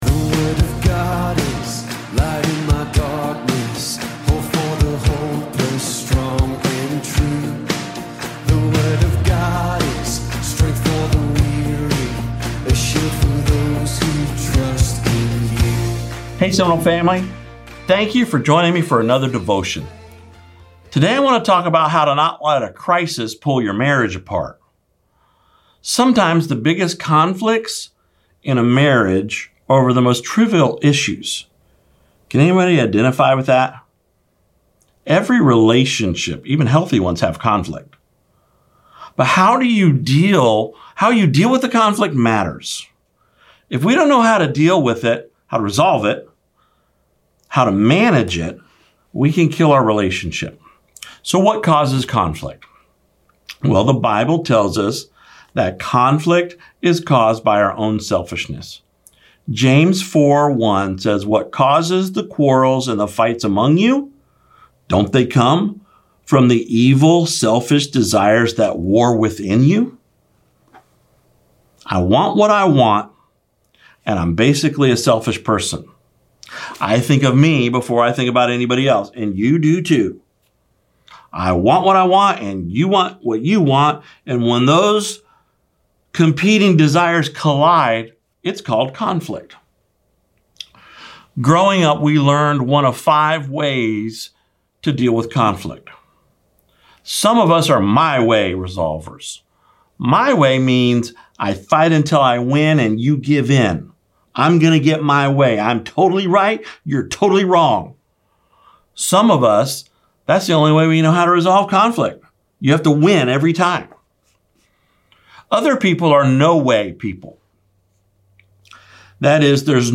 Video Devotion: Don't Let a Crisis Pull Your Marriage Apart